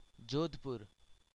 Jodhpur (Hindi pronunciation: [ˈd͡ʒoːd̪ʱ.pʊr]
Jodhpur.ogg.mp3